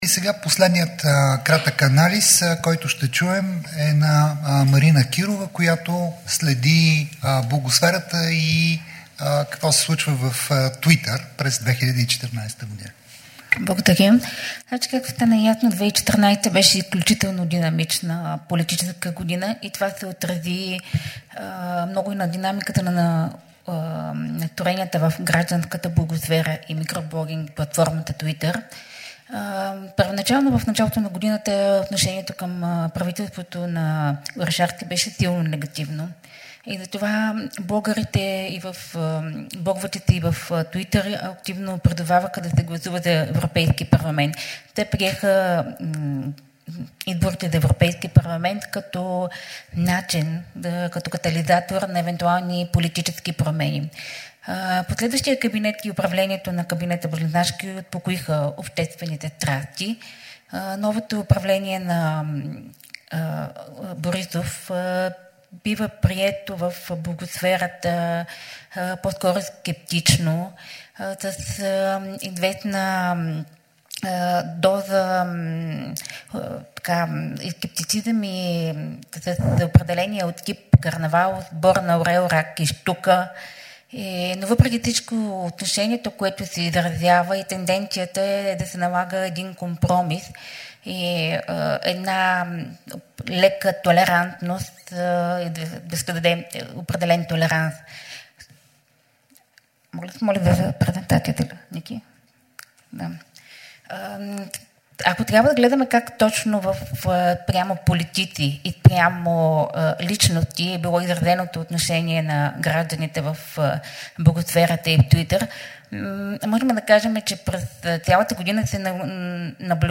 Was: Pressekonferenz der Stiftung Mediendemokratie (FMD) und des Medienprogramms Südosteuropa der Konrad-Adenauer-Stiftung (KAS)
Wo: Sofia, Presseklub der Nachrichtenagentur BTA